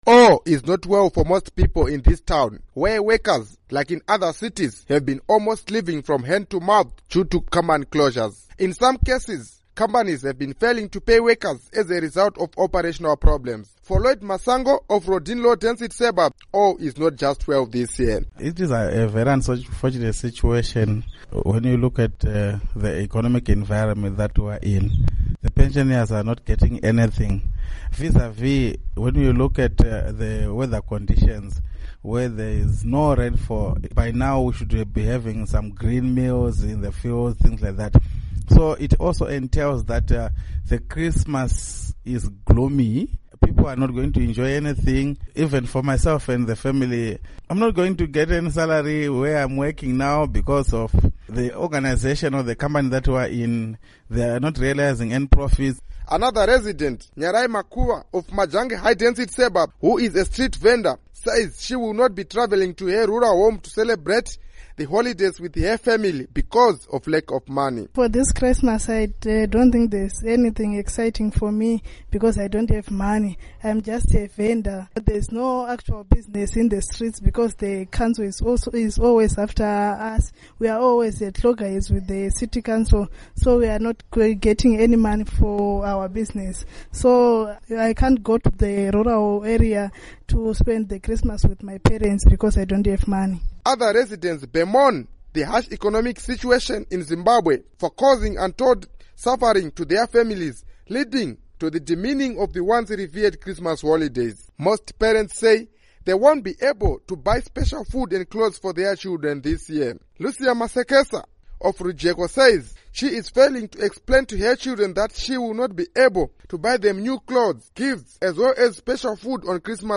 Report on Christmas in Masvingo